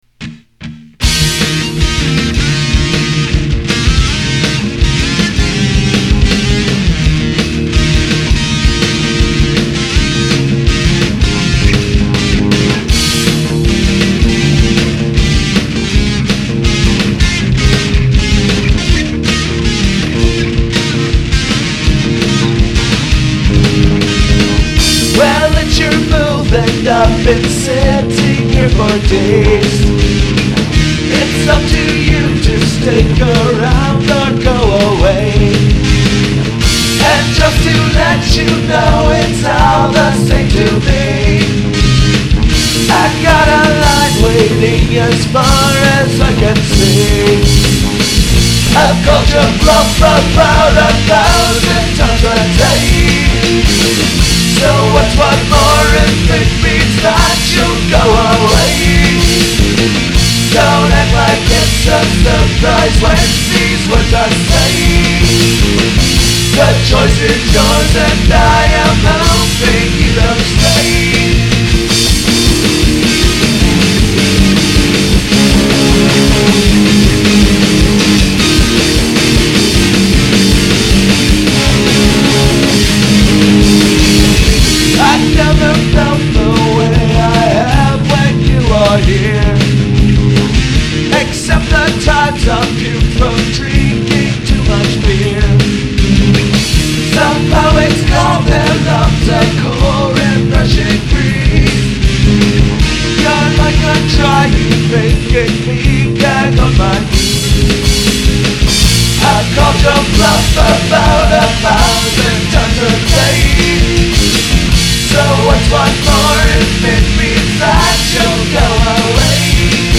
I think it's got that good alternative feel.